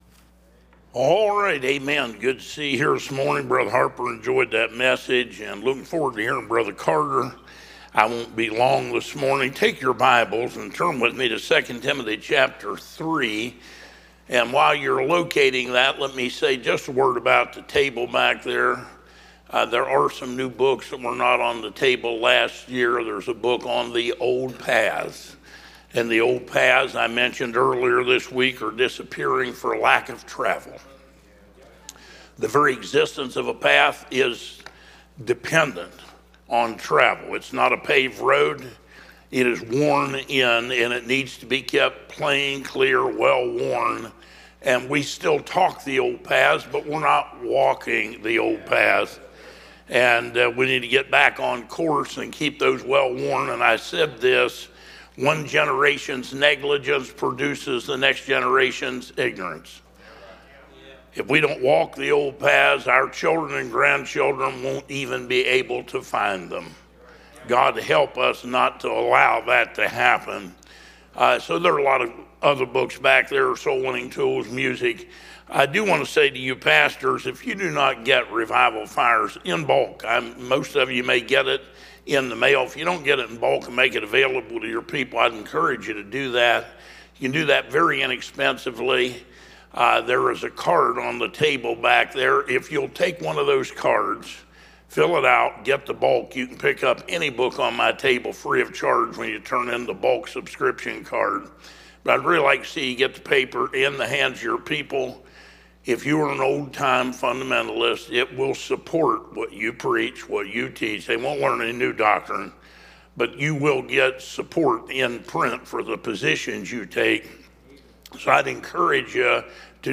Series: 2025 Bible Conference
Preacher